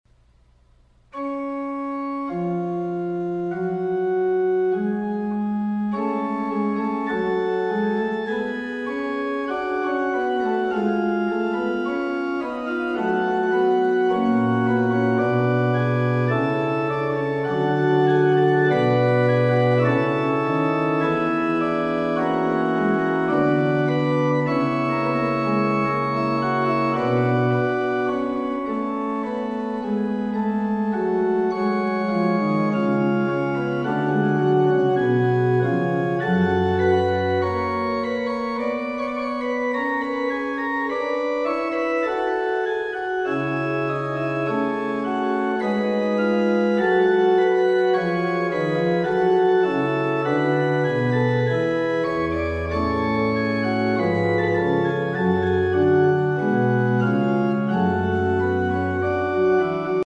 F-sharp minor